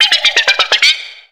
Cri d'Écayon dans Pokémon X et Y.